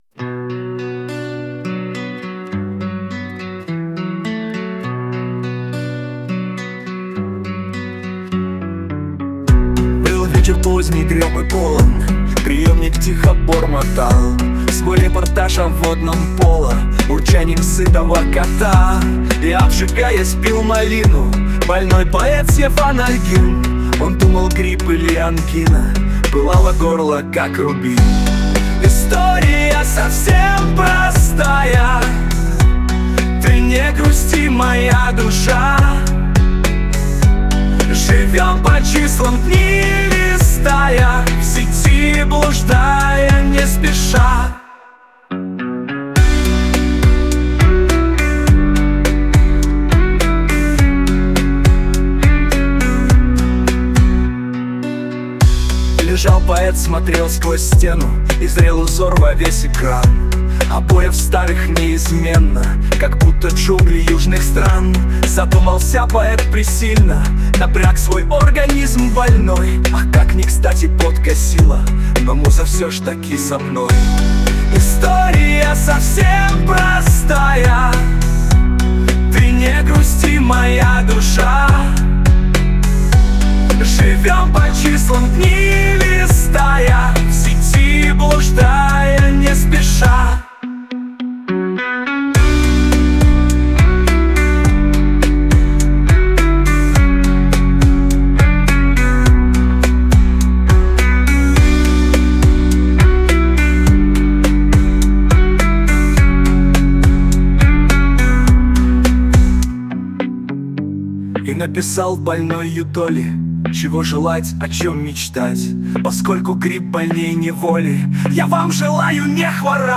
Медленный темп, 1 вариант.